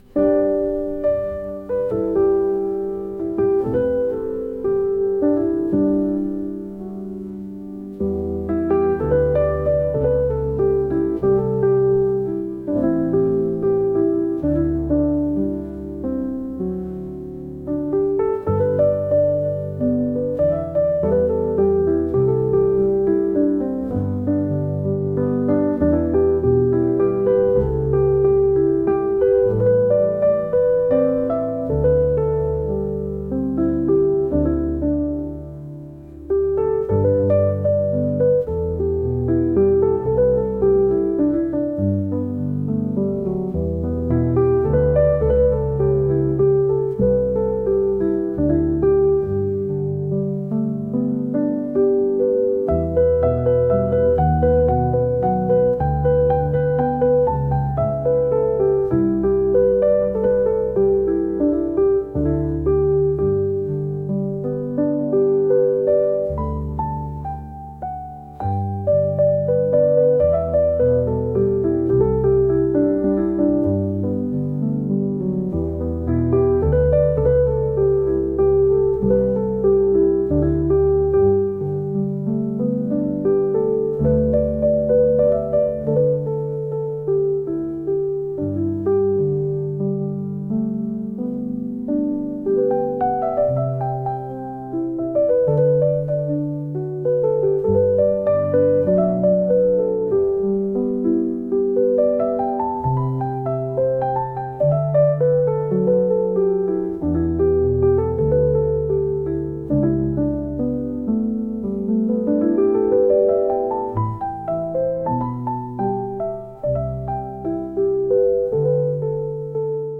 過去を思い出すようなゆったりとしたピアノ曲です。